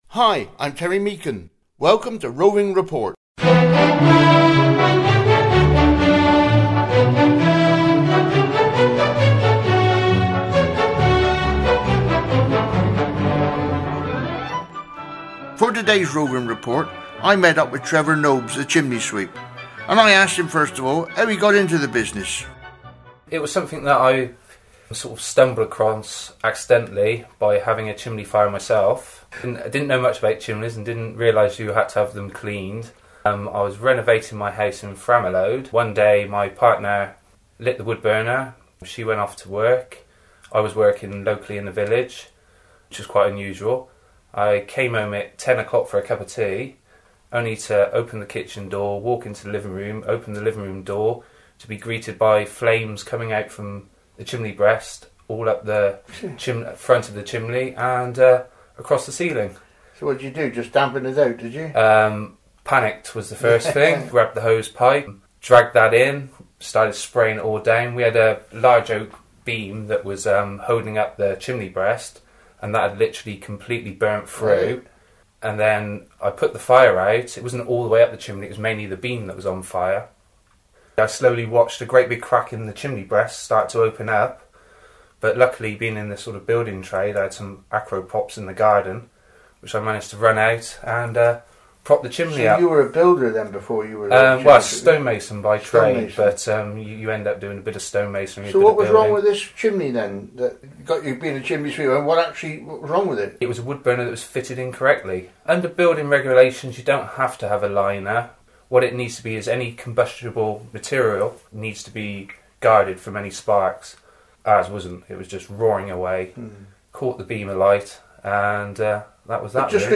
Interview with a chimney sweep
The programme has no station ID or advertisments and can be downloaded for re-broadcasting throughout the world as a Public Service or for individual listening